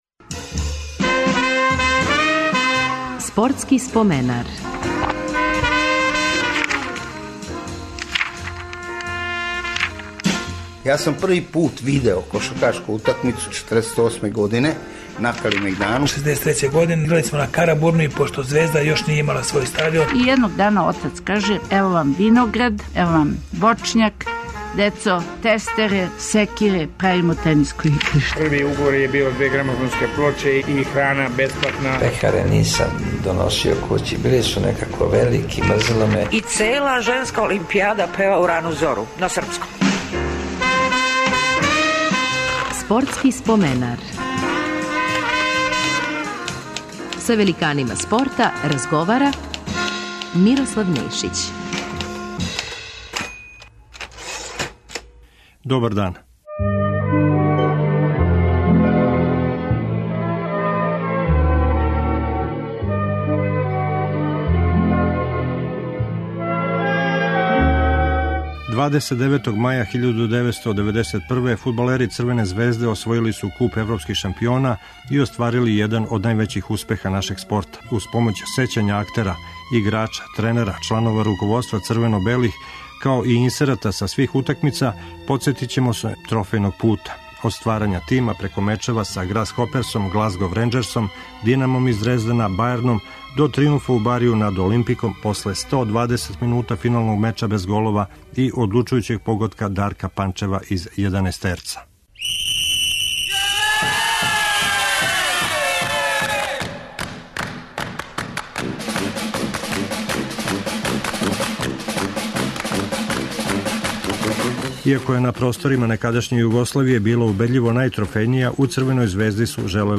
Уз помоћ сећања Драгана Џајића, Стевана Стојановића, Љупка Петровића и Миодрага Белодедића снимљених специјално за ову емисију, као и изјава Дејана Савићевића, Дарка Панчева, Роберта Просинечког... из времена похода црвено-белих ка европском врху, инсерата из преноса свих утакмица, подсетићемо вас на победе против Грасхоперса, Глазгов ренџерса, Динама из Дрездена, Бајерна и Олимпика.